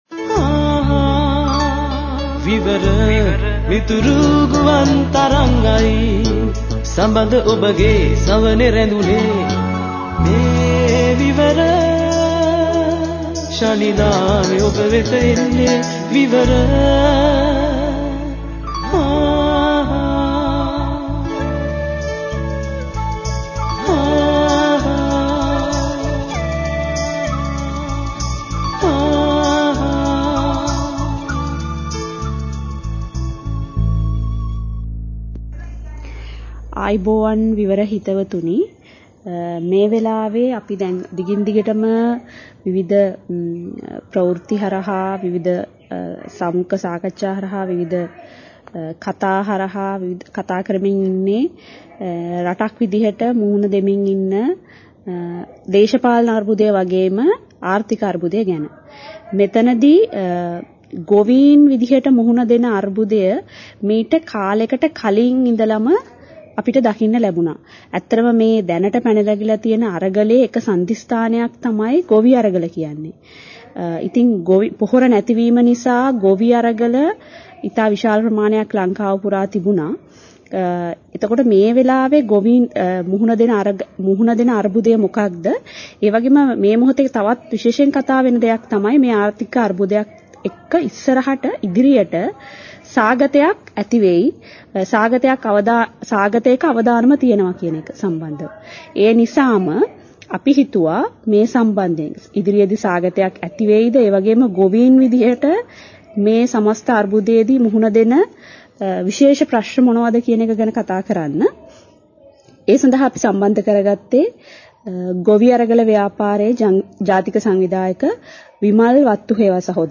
ගෝඨාභය මහින්ද වන් තකතීරු වන්ගේ මෝඩ තීන්දු තීරණ වල ප්‍රතිඵල තමයි අද අපිට භුක්ති විඳින්න සිද්ධ වෙලා තිබෙන්නේ මෙන්න මේ වටපිටාව යටතේ අපි කැමති ඔබ සමග අද ගොවි ජනතාව පත් වී තිබෙන මුහුණ දී ඉන්න ඉරණම පිළිබඳව යම් කතාබහ.